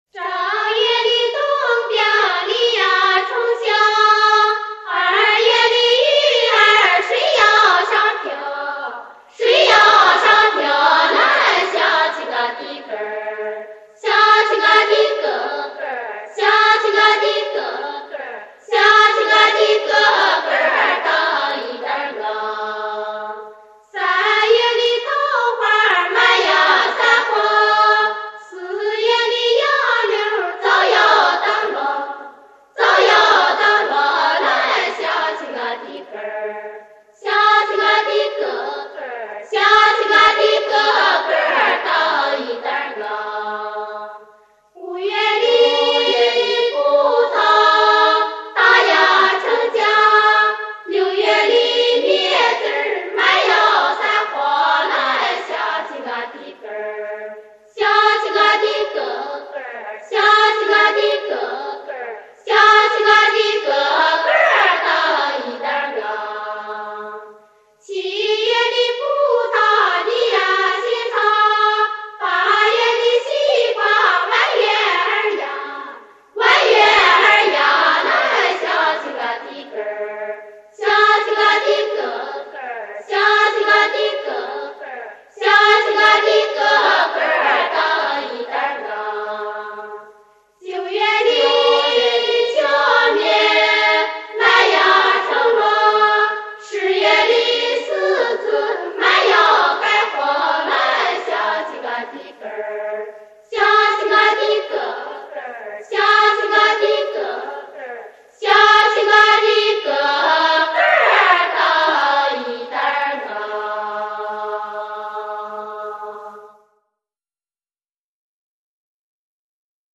这两张CD是中国唱片根据录音资料，HDCD处理后的，限于当时条件，录音不尽如意，好在表演质量一流，尤其是CD中收录的5首陕北民歌无伴奏合唱，由中央歌舞团民间合唱队演唱，原汁原味，值得特别推荐。
12.对花（无伴奏合唱）
陕西省歌舞团合唱队演唱